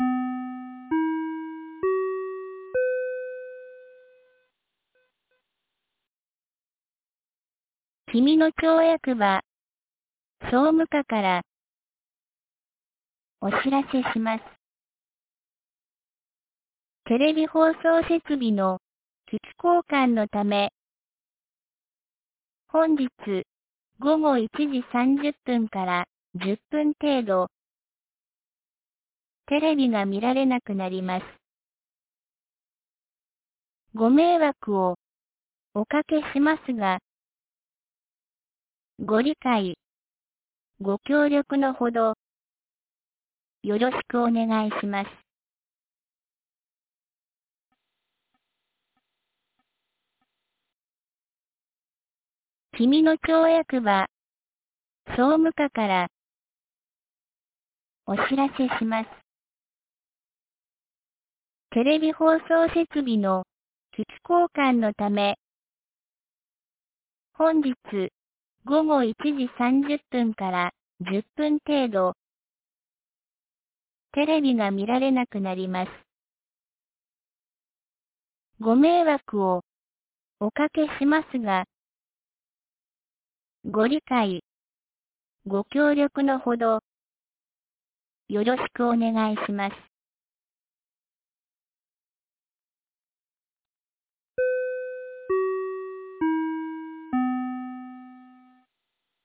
2025年09月05日 12時31分に、紀美野町より長谷毛原地区へ放送がありました。